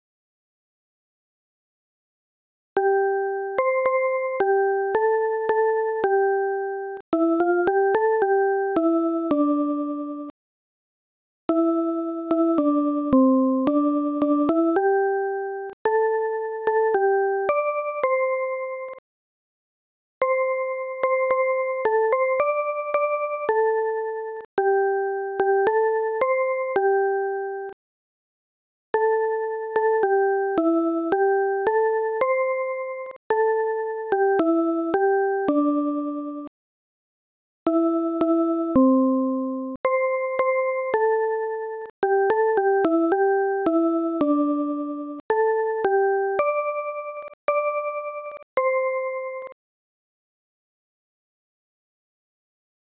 （四部合唱用編曲）
（注：データー簡略化のため、付点８部音符＋１６部音符→８部音符＋８部音符に変更しています。）
ソプラノ